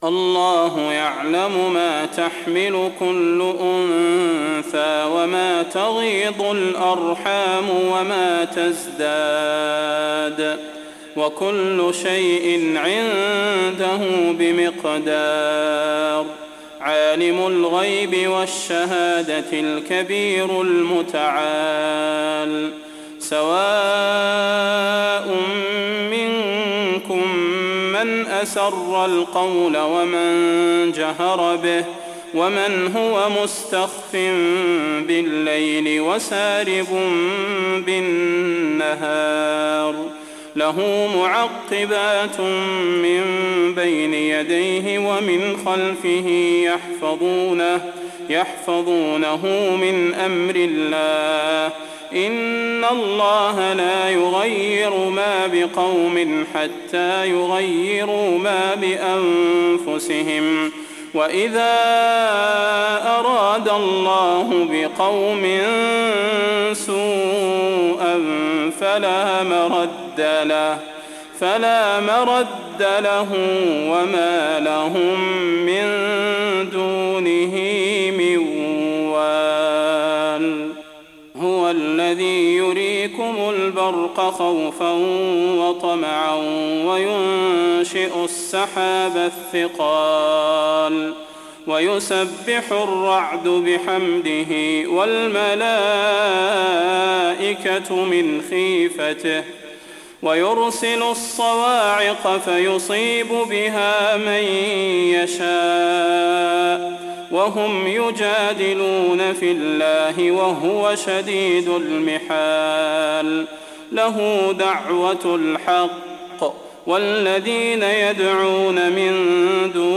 فروض مغرب البدير 1420